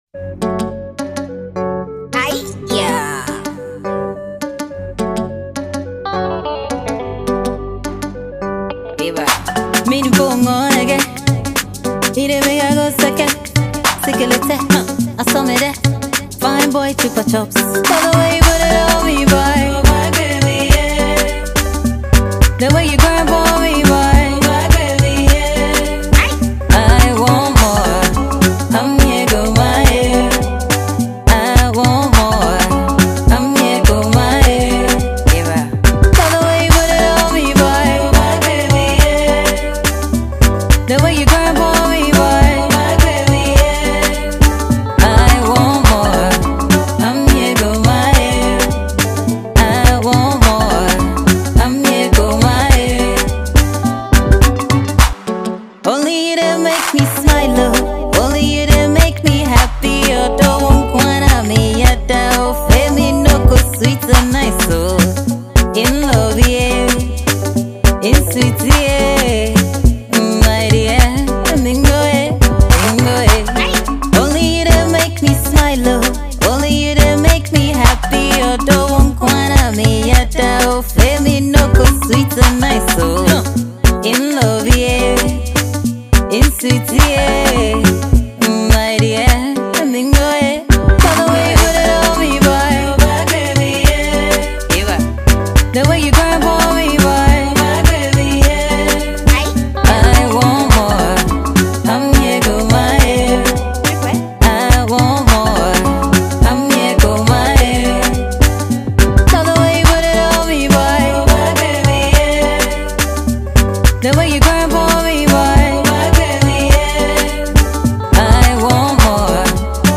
Ghanaian top-notch female signer